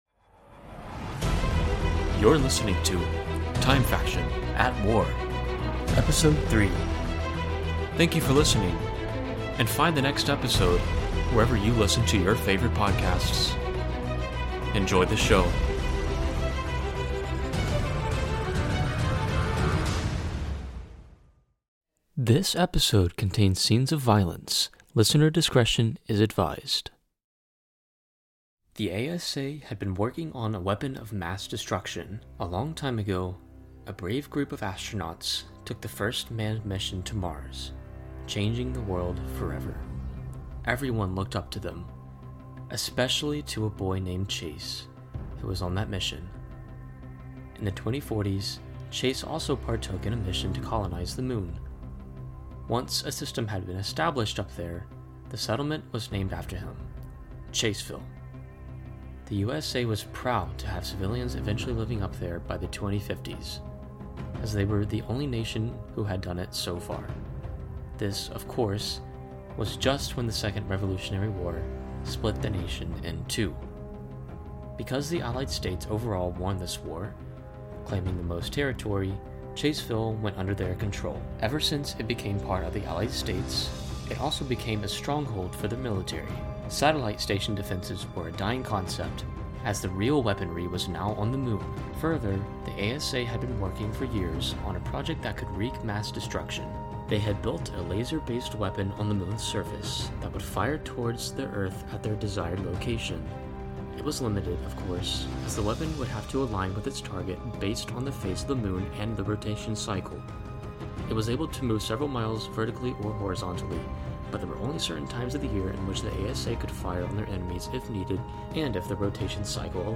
Silenced Gun:1
01578 distant creepy movement.wav
clicks and pops 6.aif
Huge Explosion.wav
Scifi Laser Gun Firing
FX_Metallic_hit.wav